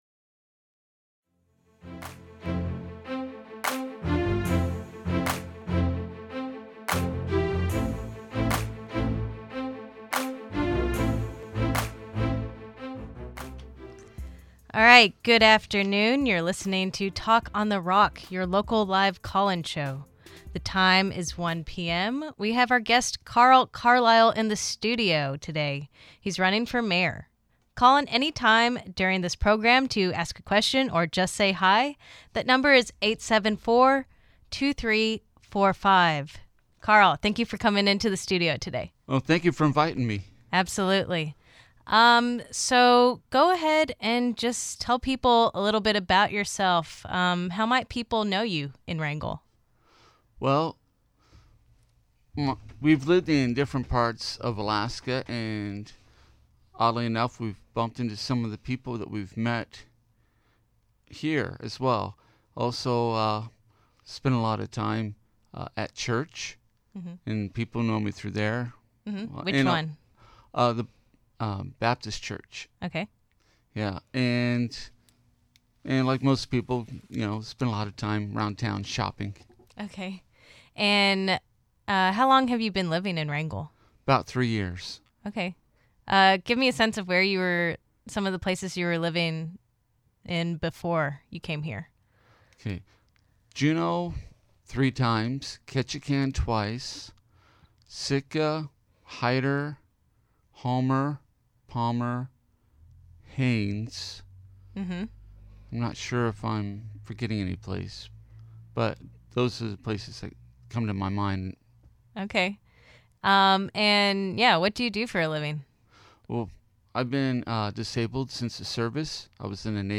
Talk on the Rock is Wrangell's live call-in show.